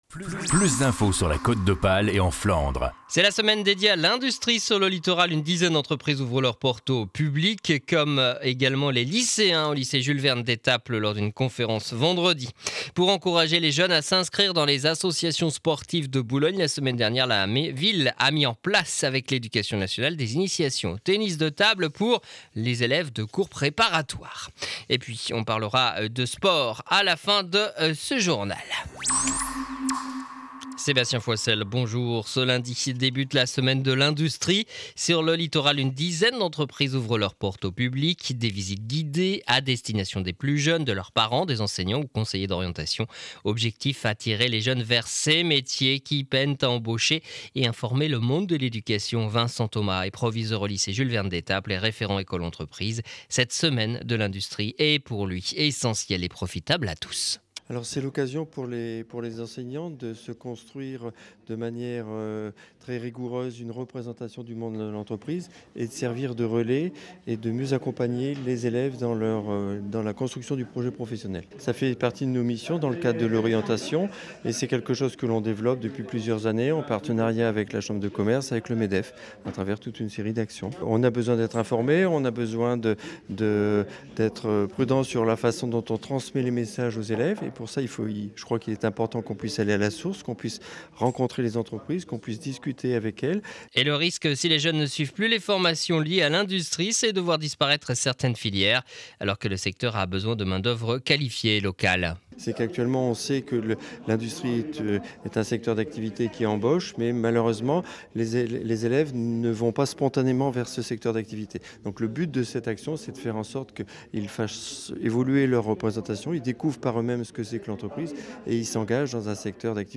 Journal du lundi 19 mars 2012 7 heures 30 édition du Boulonnais.